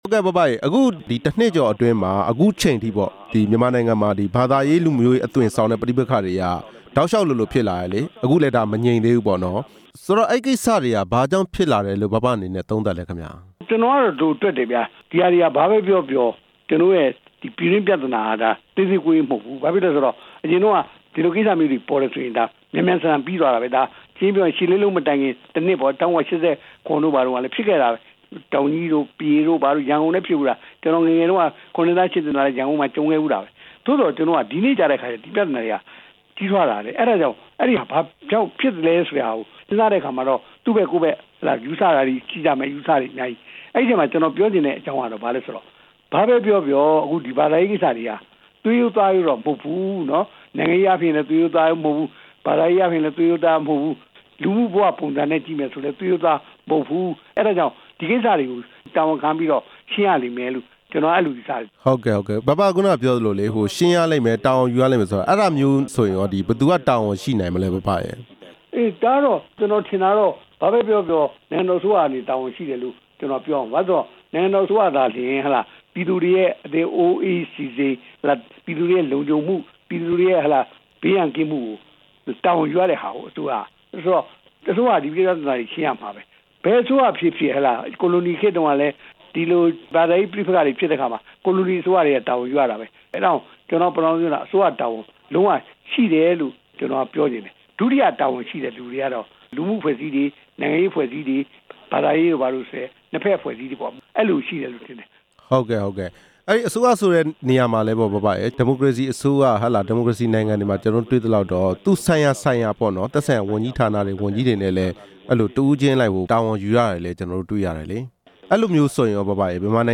ဘာသာရေး၊ လူမျိုးရေးအသွင်ဆောင်တဲ့ ပဋိပက္ခတွေနဲ့ ပတ်သက်လို့ ဦးဝင်းတင်နဲ့ ဆက်သွယ် မေးမြန်းချက်